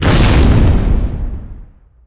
explodeArea.mp3